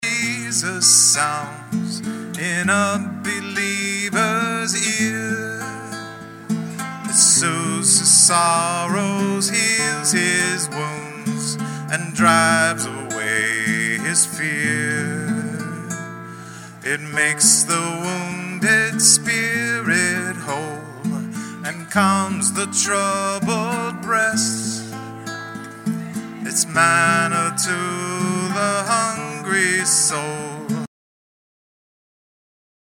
recorded IN CONCERT